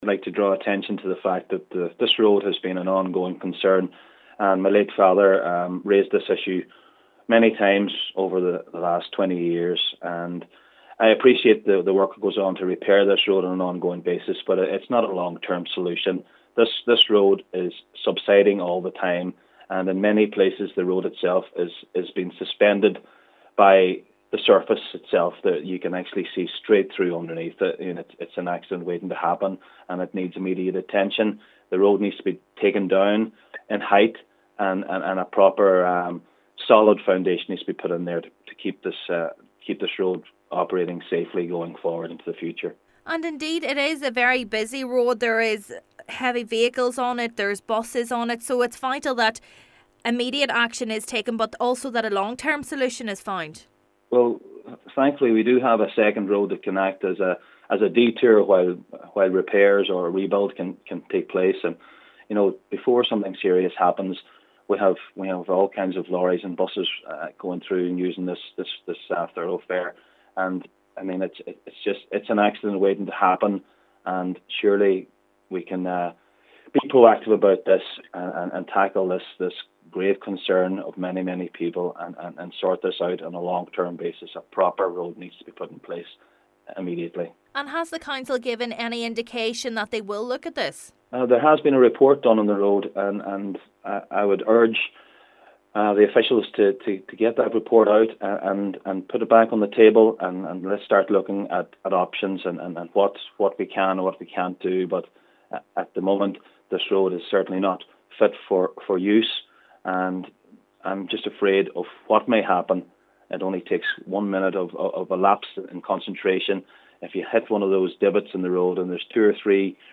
An Inishowen Councillor has warned that unless immediate action is taken to repair a road in Culdaff, someone is going to be seriously injured or worse.